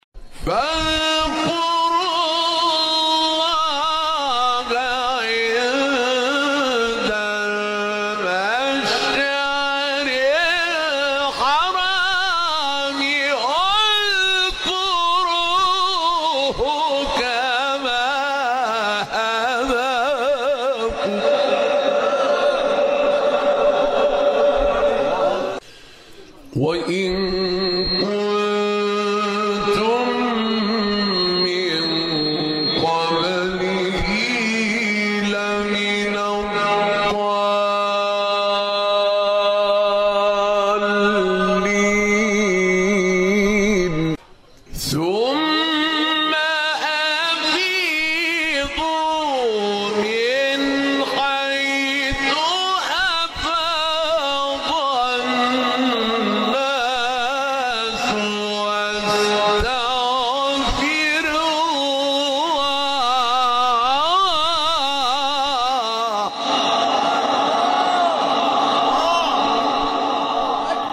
مقام : بیات